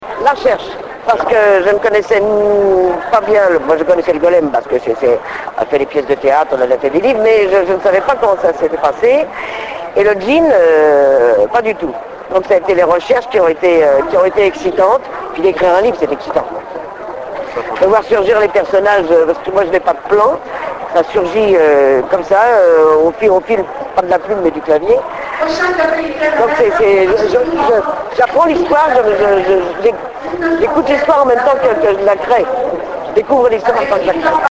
Interview Maud Tabachnik - Mai 2007